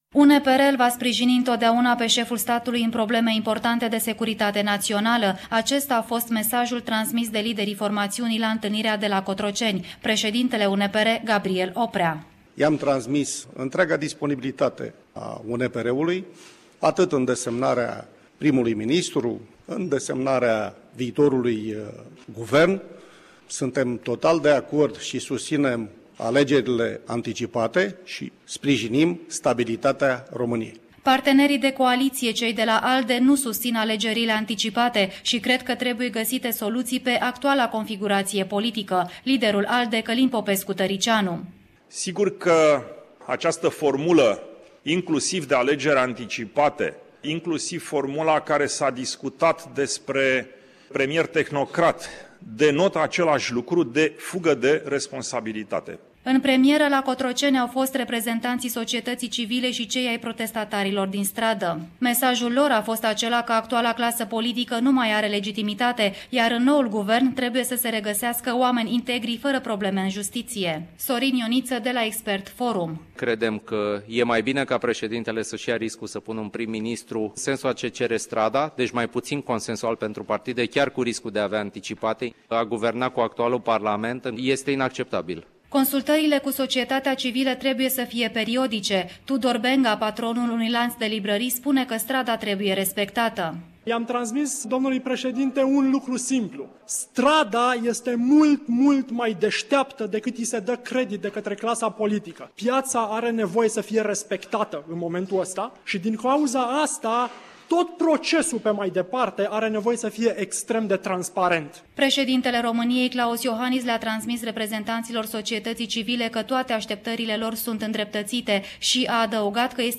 Redactorul Radio România